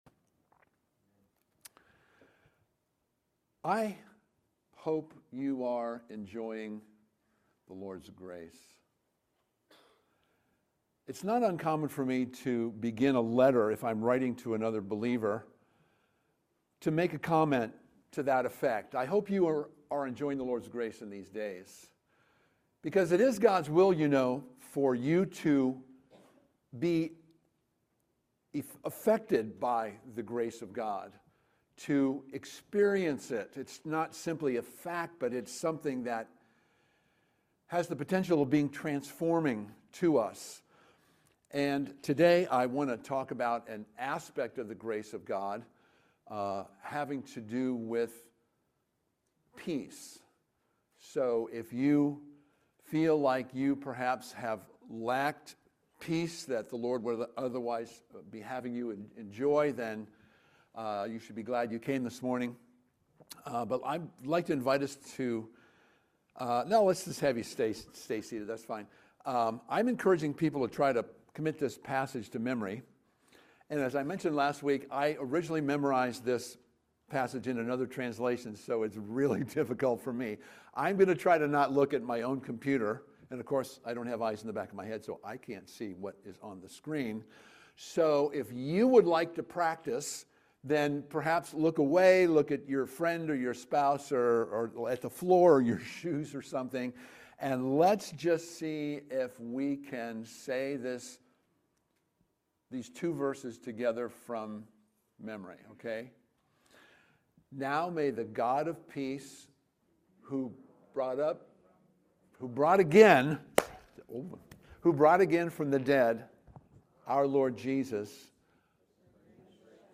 Sermons | New Song Community Church